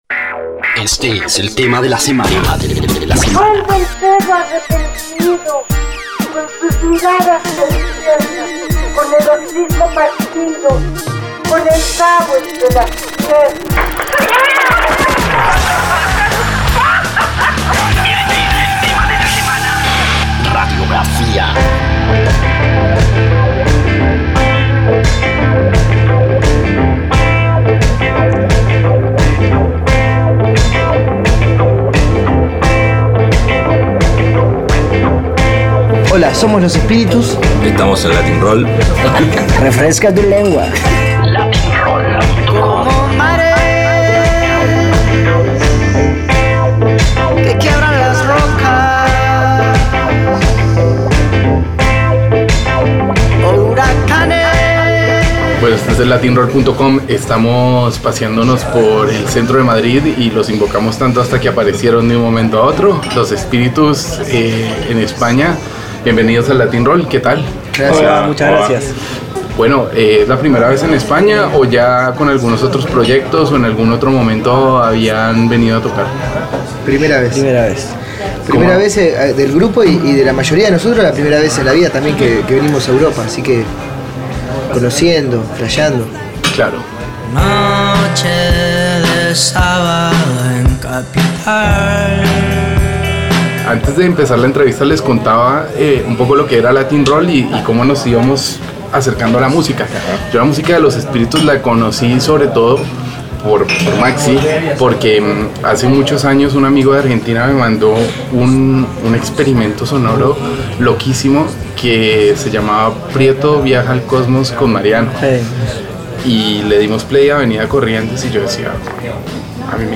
Escucha la entrevista con Los Espiritus aquí: